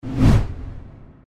impact3.mp3